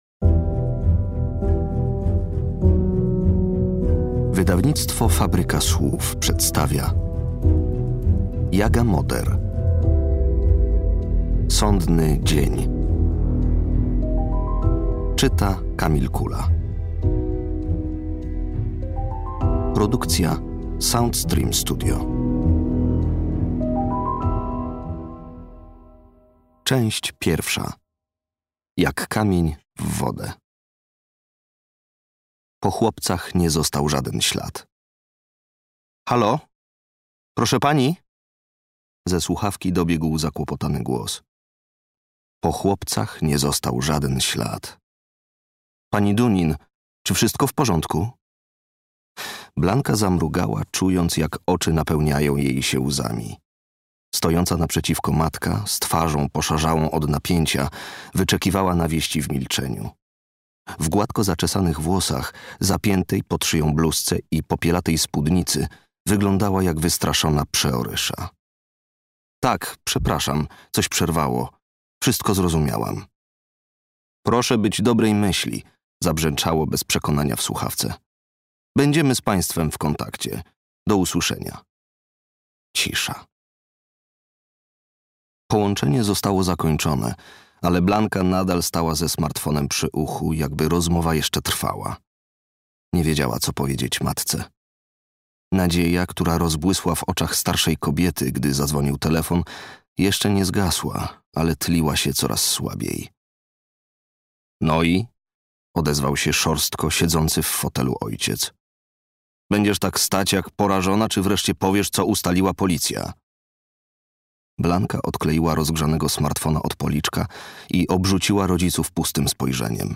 Sądny dzień - Jaga Moder - audiobook